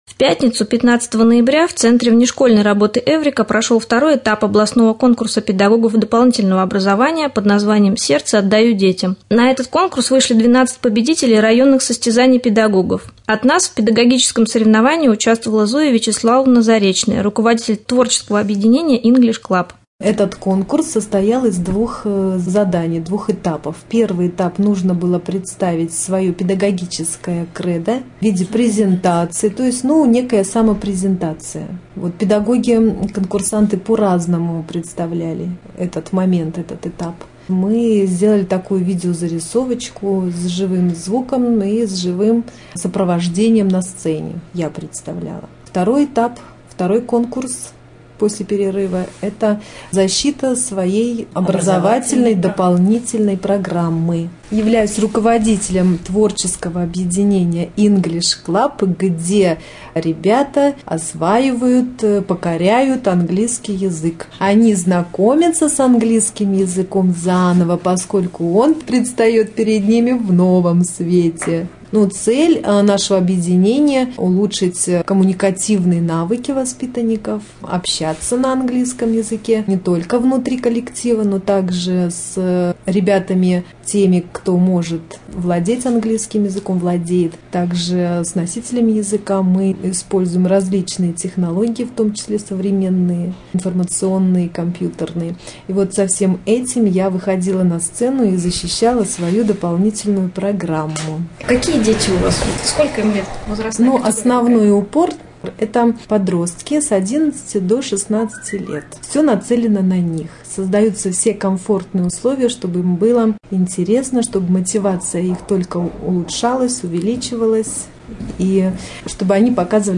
4.Рубрика «Наш репортаж». В центре внешкольной работы «Эврика» прошел конкурс педагогов дополнительного образования.